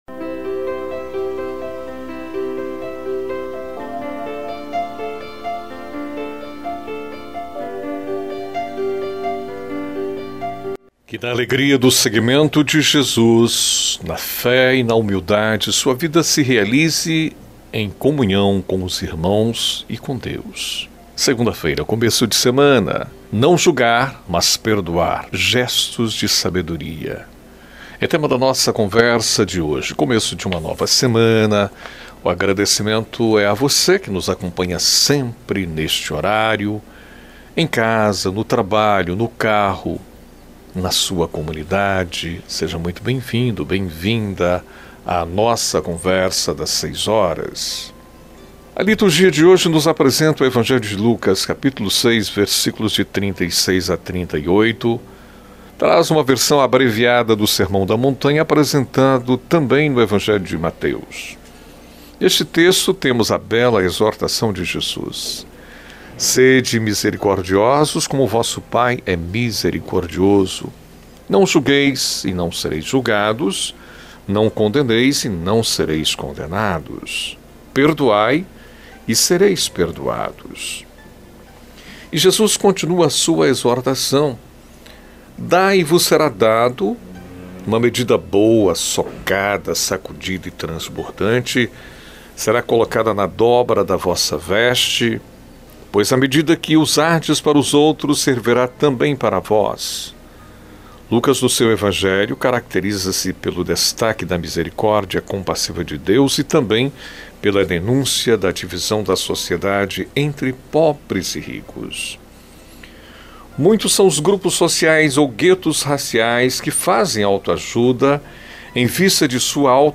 na voz do locutor